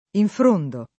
infr1ndo] — voce lett. per «coprire di fronde» — un es. di infrondarsi «coprirsi di fronde»: Le fronde onde s’infronda tutto l’orto De l’ortolano etterno [le fr1nde onde S infr1nda t2tto l 0rto de ll ortol#no ett$rno] (Dante) — in questo sign., anche (intr.) infrondire: infrondisco [